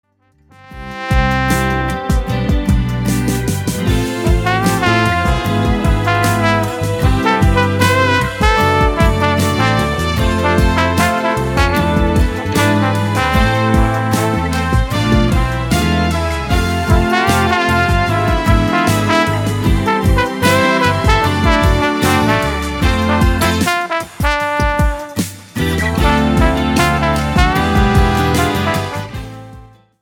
POP  (03.30)